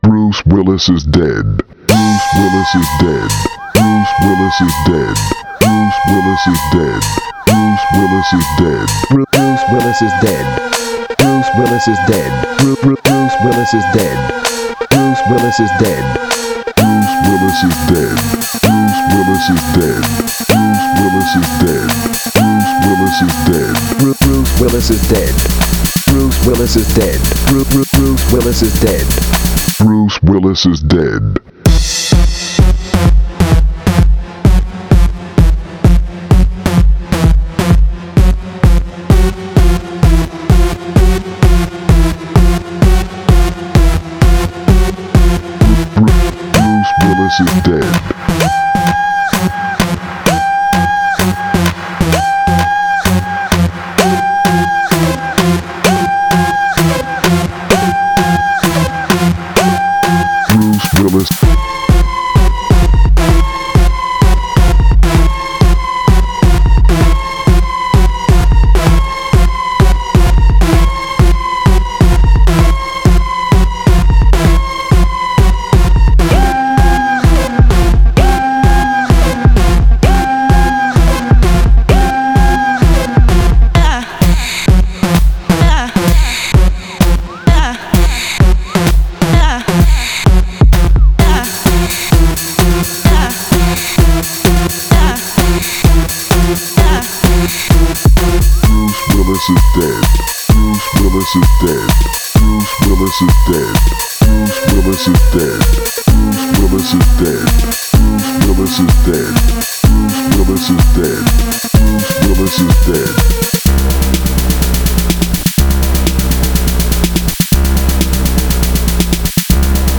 Filed under bangers